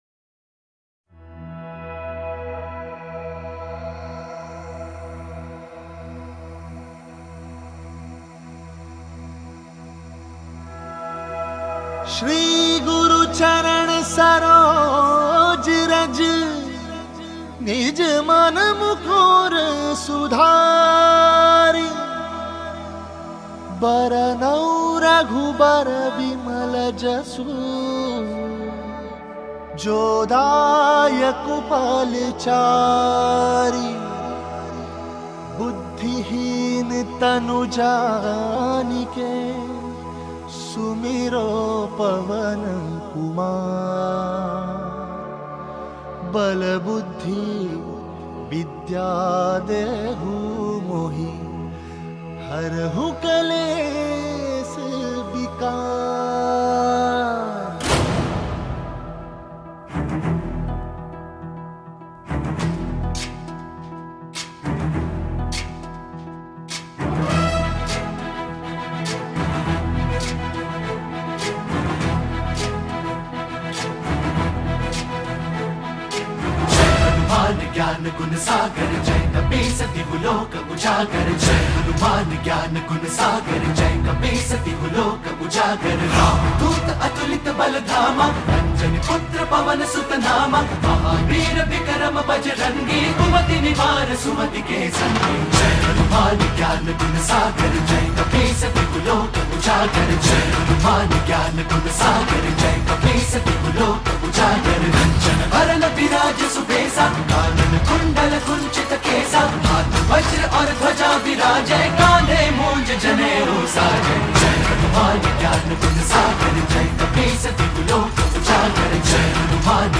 мощную духовную песнь